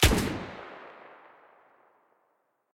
sounds_rifle_fire_03.ogg